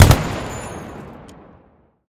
gun-turret-shot-7.ogg